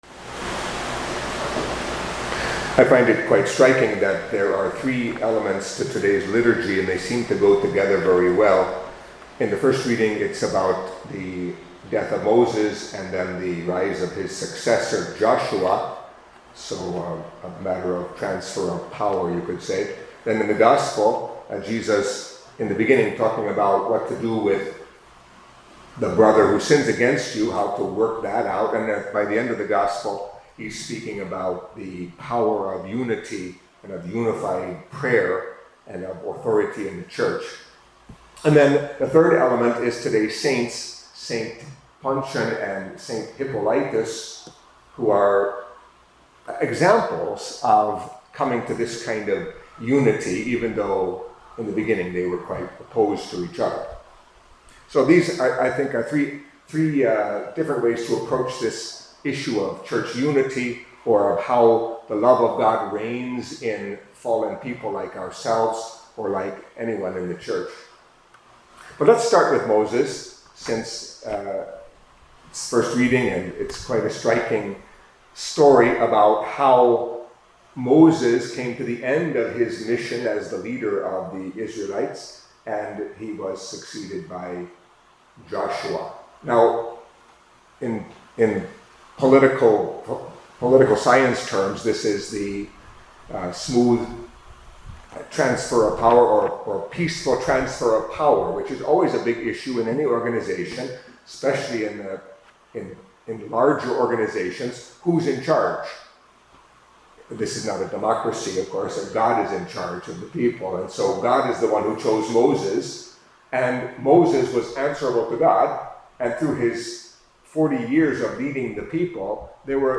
Catholic Mass homily for Wednesday of the Nineteenth Week in Ordinary Time